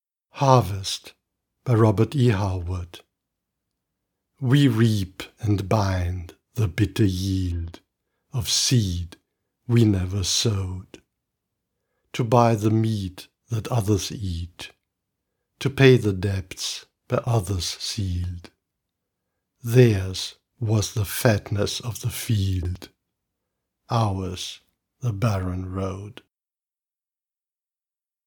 Audio Recordings of Poems by Robert E. Howard